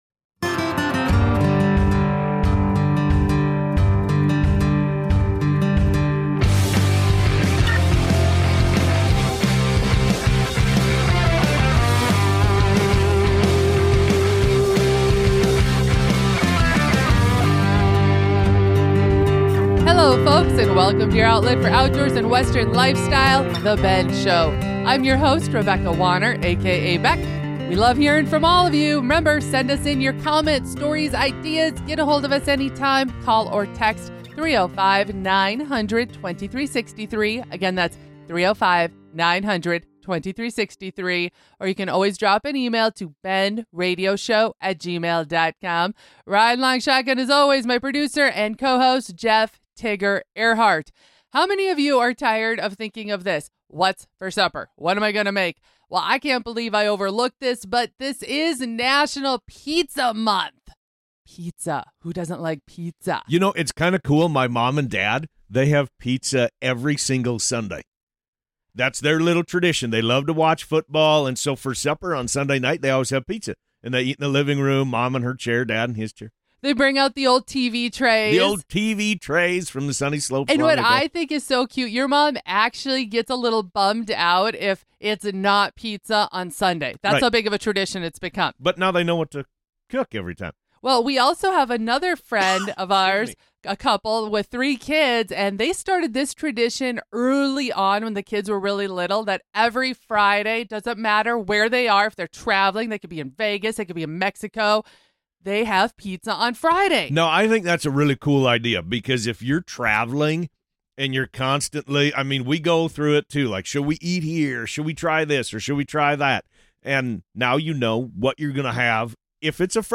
The Bend Radio Show and Podcast Episode 259